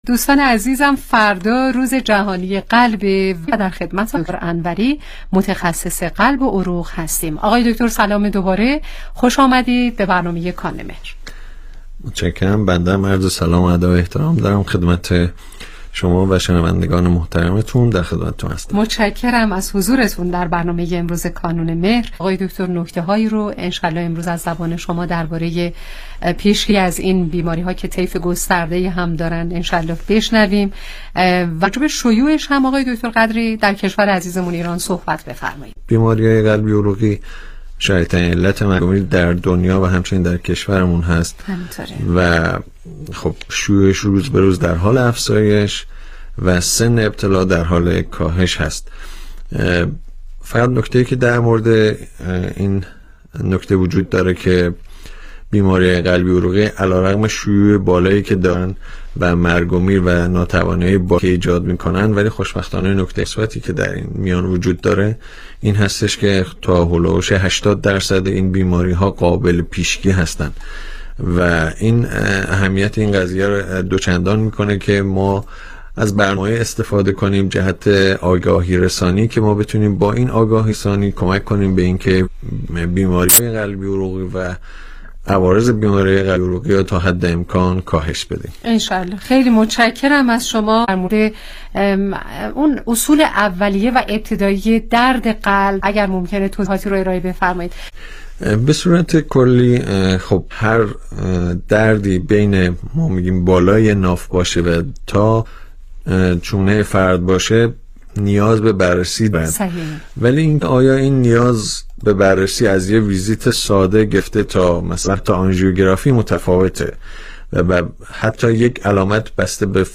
برنامه رادیویی کانون مهر